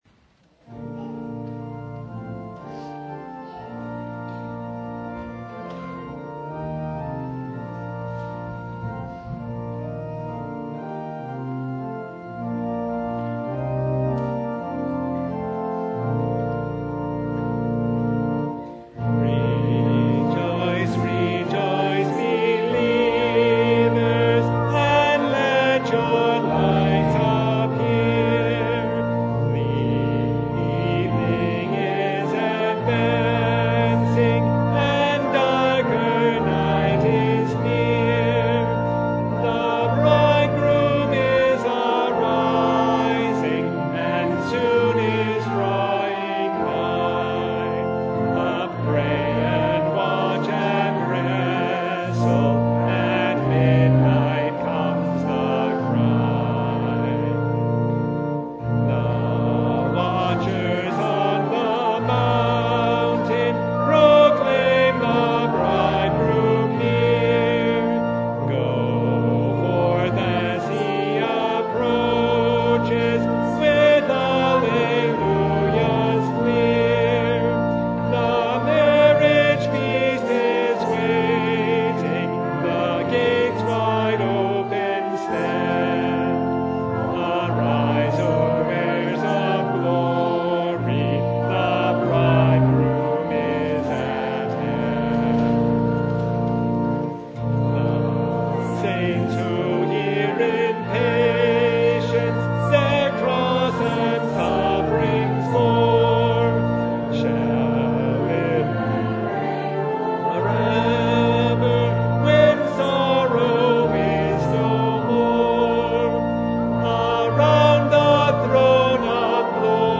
Service Type: Advent Vespers
Topics: Full Service